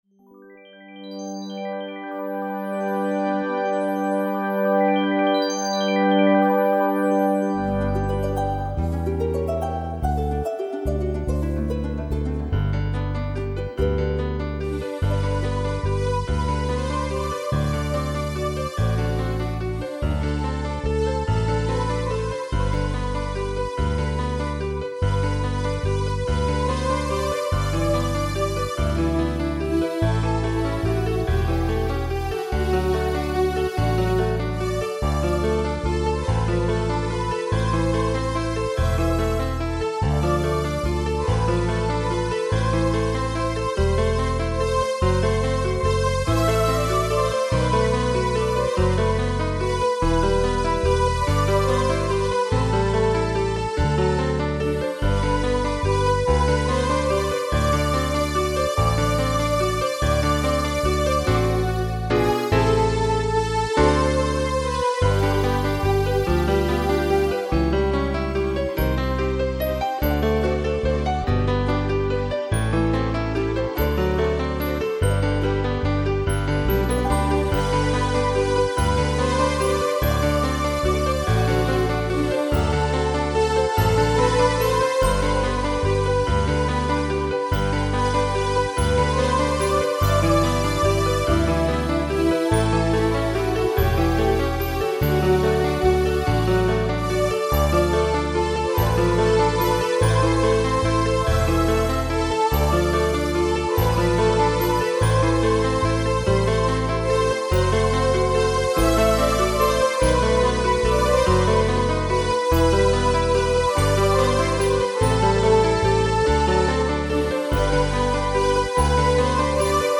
Keyboard und Synthesizer-Klassik
Keyboard und Synthesizer